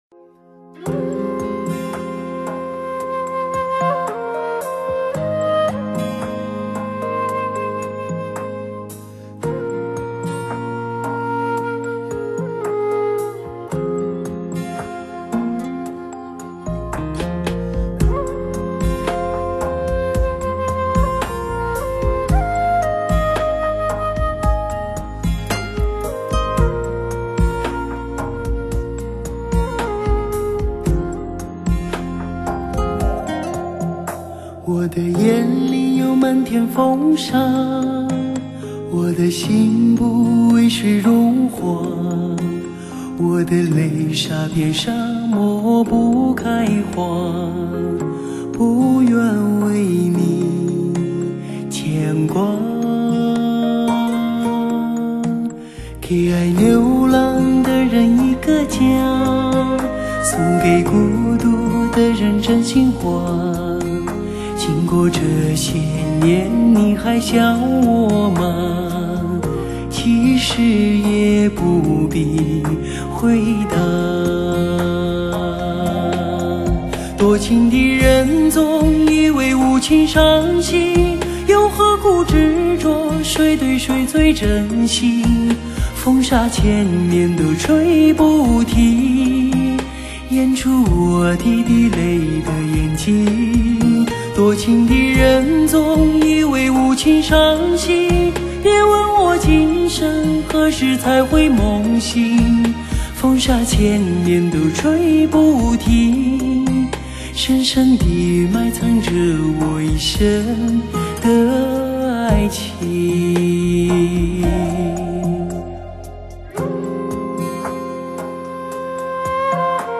华语发烧靓声，乐迷票选榜首的最爱潮流热曲，纯净发烧的音乐质感